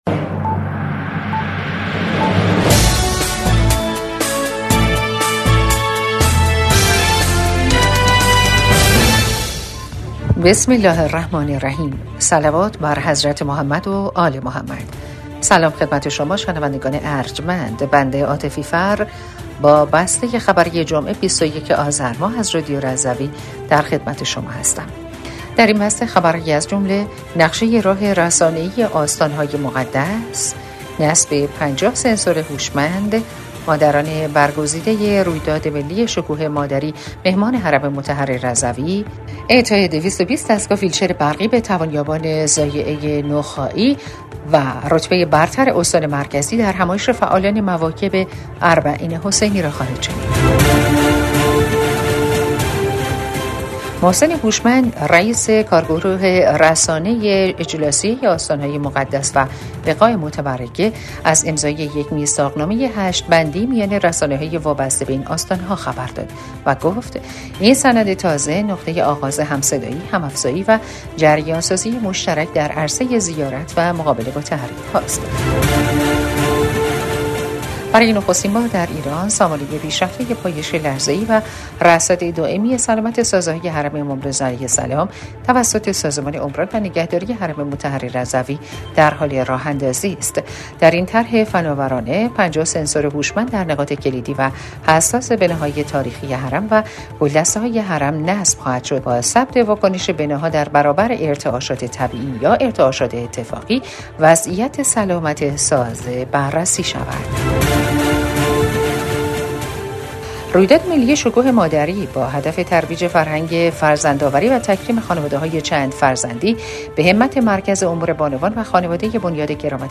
بسته خبری هفتگی ۲۱ آذر ۱۴۰۴ رادیو رضوی؛